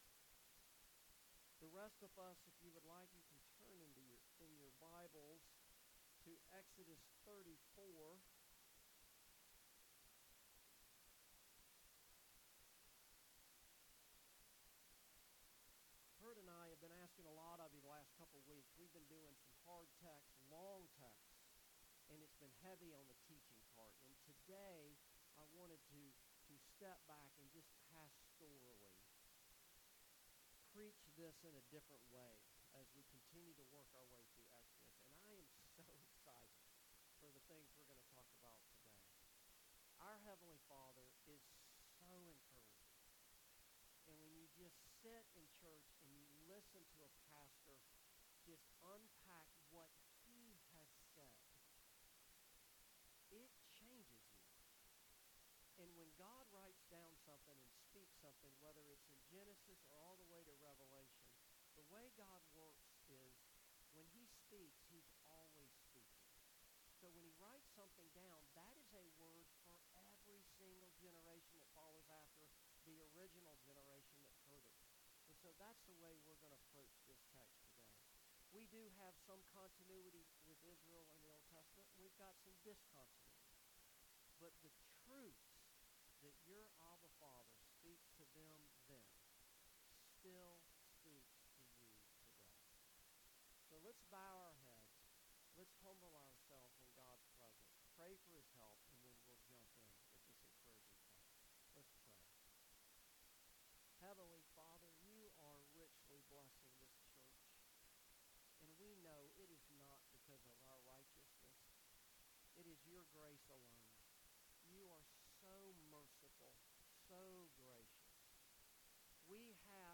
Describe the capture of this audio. Passage: Exodus 34:6-24 Service Type: Morning Service